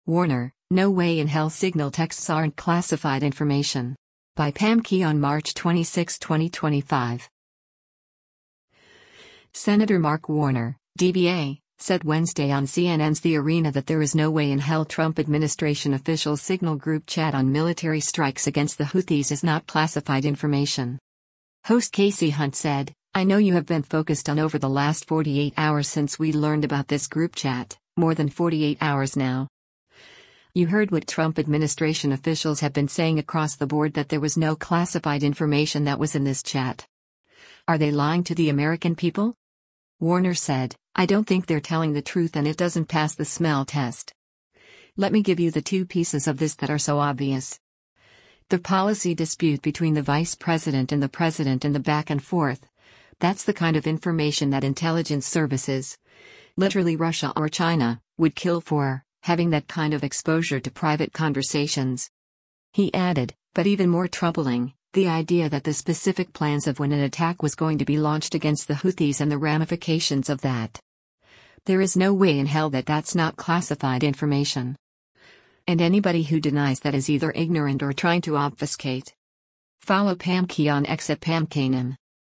Senator Mark Warner (D-VA) said Wednesday on CNN’s “The Arena” that there is “no way in hell” Trump administration officials’ Signal group chat on military strikes against the Houthis is not classified information.